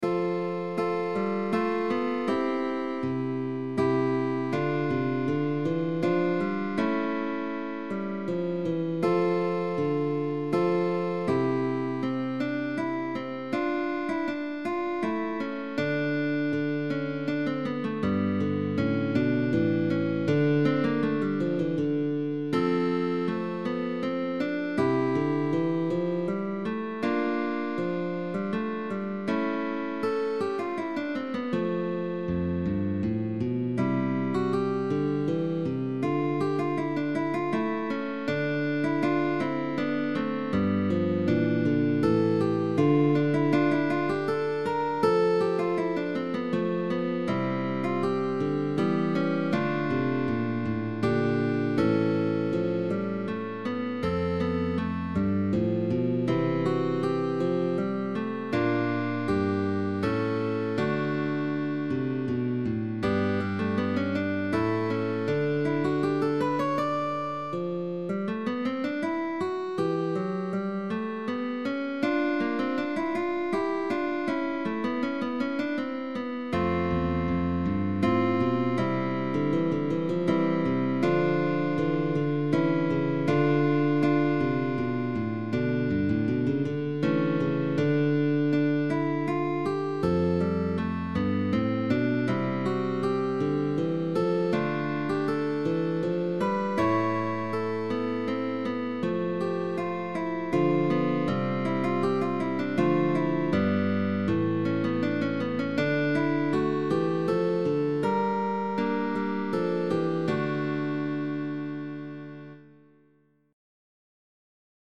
Romanesca (Mudarra) FREE 0,00 € GUITAR TRIO Alternation of m and i. Rest Stroke.
Early music